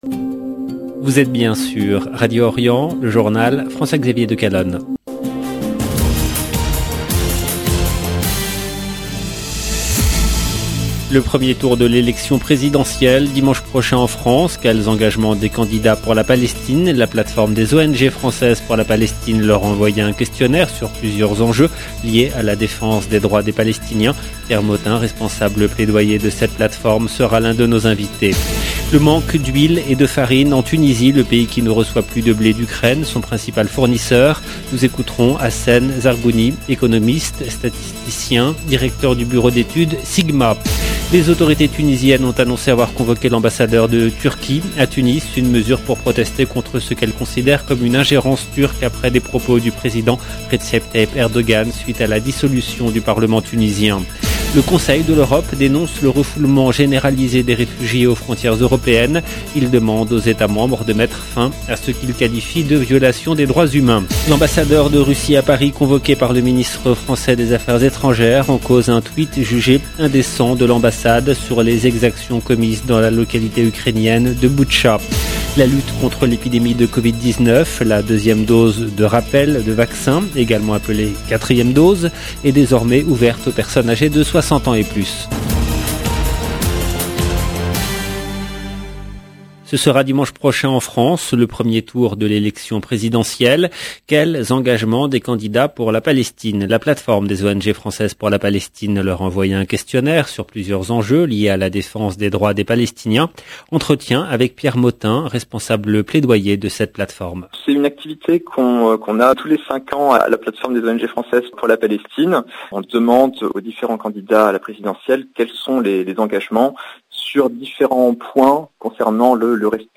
LE JOURNAL DU SOIR EN LANGUE FRANCAISE DU 7/4/2022